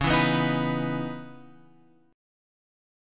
C# Minor 9th